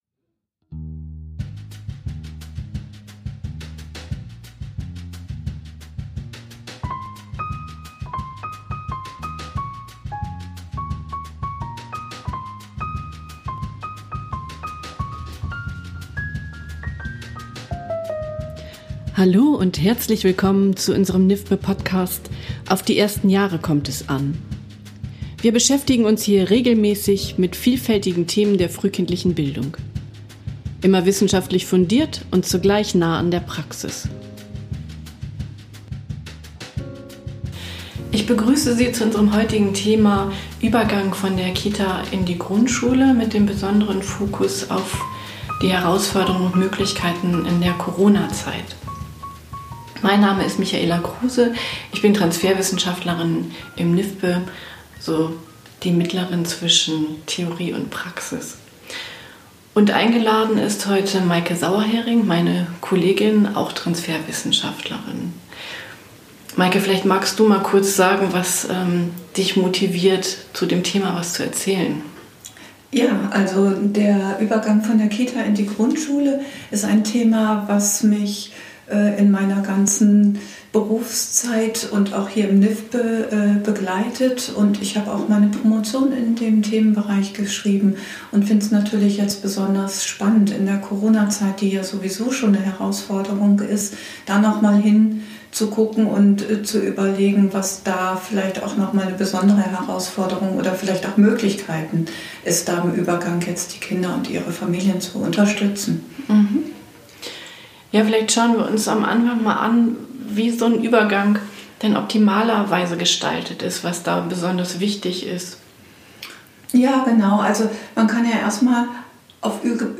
In diesem Podcast wird den Fragen nachgegangen, was die Besonderheiten eines solchen Übergangs sind und was Kinder und ihre Familien für Unterstützung benötigen. Darüber hinaus wird erörtert, welche Herausforderungen sich aktuell stellen und welche Möglichkeiten der Begleitung durch Eltern und Lehr- und Fachkräfte bestehen. Podcast-Moderatorin